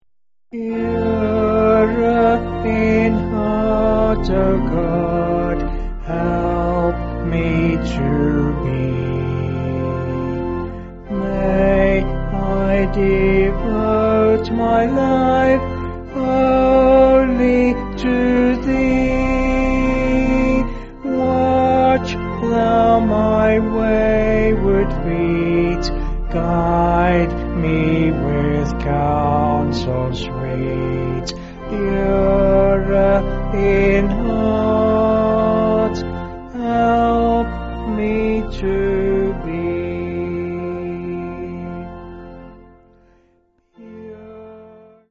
Vocals and Band   263.6kb Sung Lyrics 2.2mb